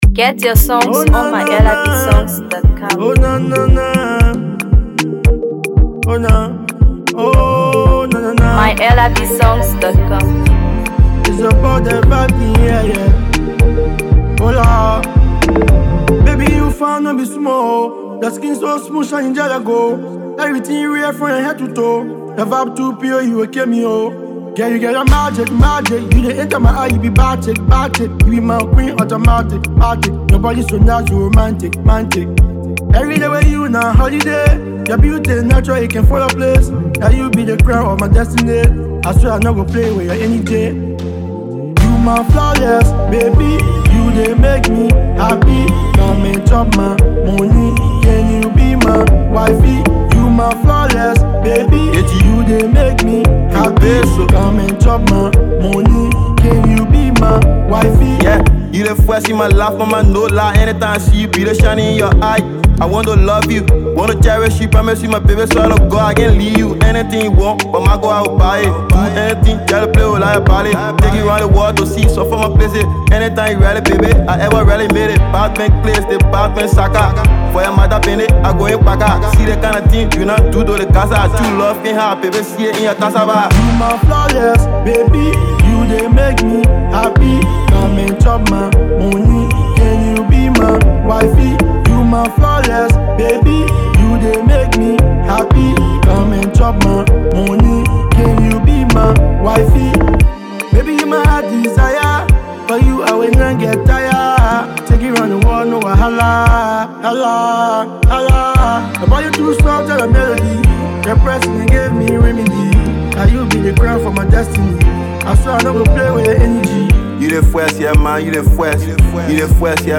Afro Pop
smooth Afrobeat love song
With warm melodies, catchy rhythms, and heartfelt lyrics
young and talented rapper